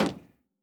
added stepping sounds
LowMetal_Mono_05.wav